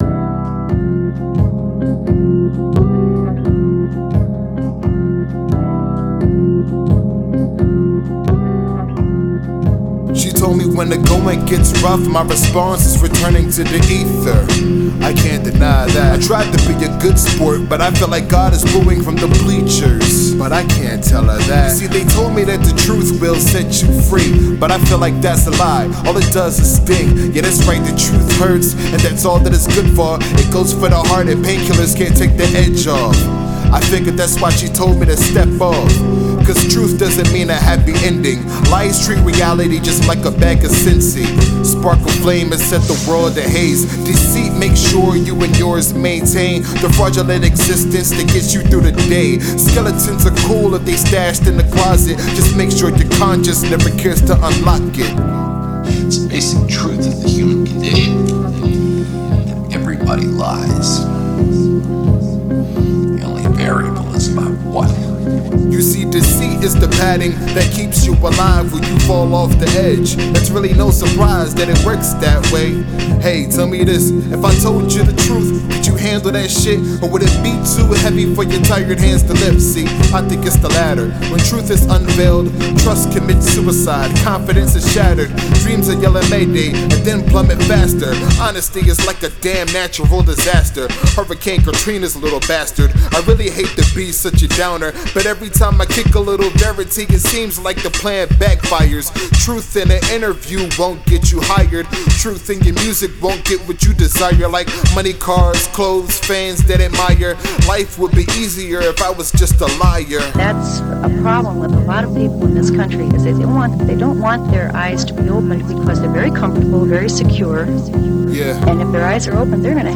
It’s very existential, moody, and self-indulgent.